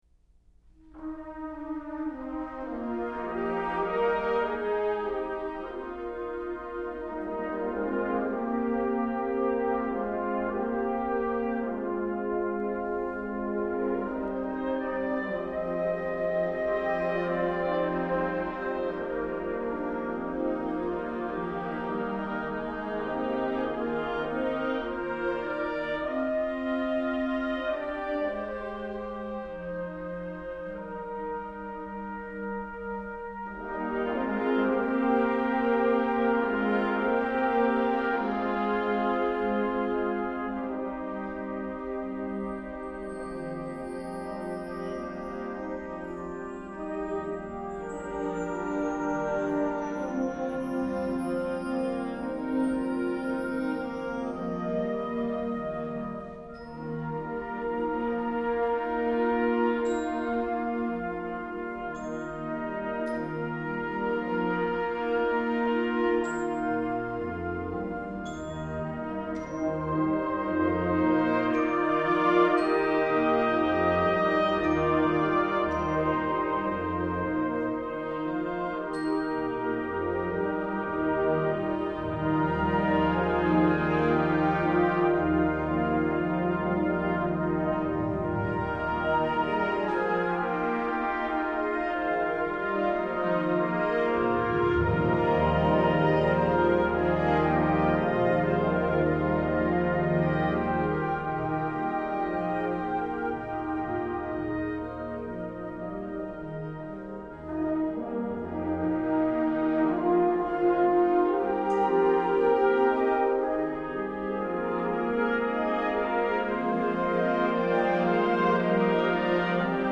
Genre: Band
a soft and touching piece for developing band
Percussion 1(crotales, bells, mark tree)
Percussion 2 (vibraphone, mark tree)